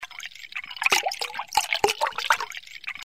pee_1.mp3